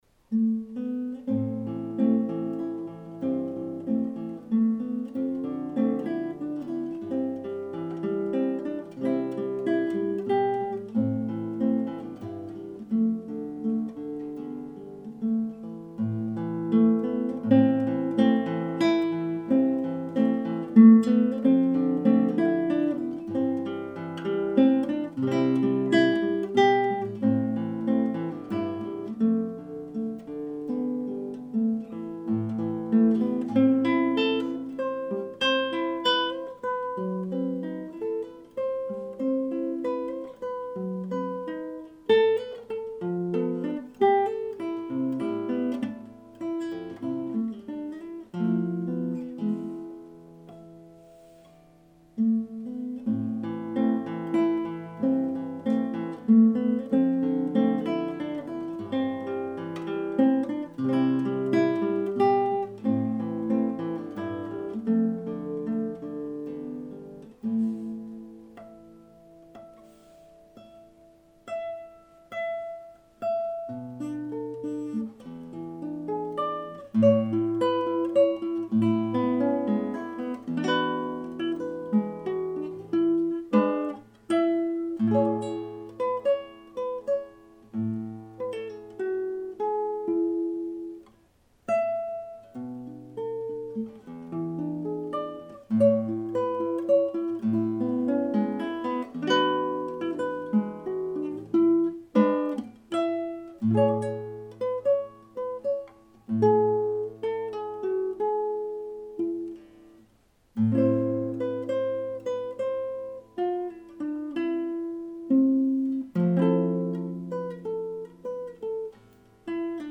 Parisian guitar player
in one take with a Sony Minidisc and a tiny Sony mike
played on guitar #10 (asymmetrical)